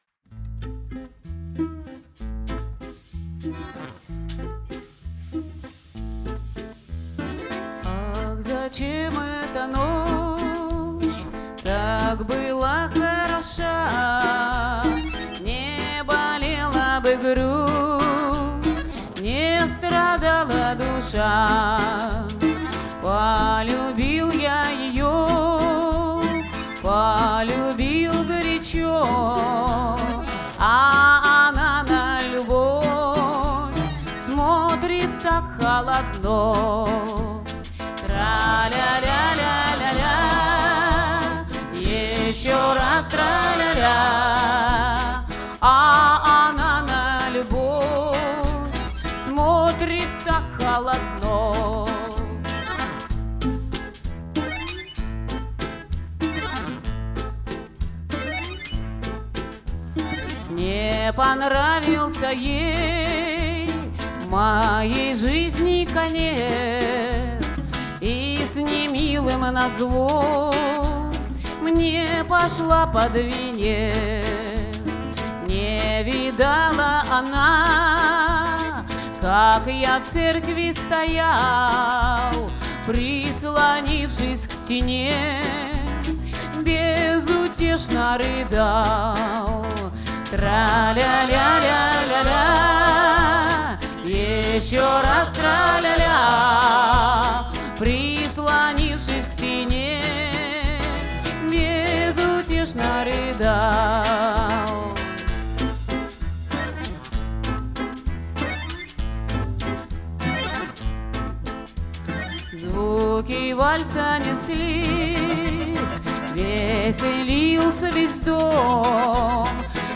виолончель
баян
гитара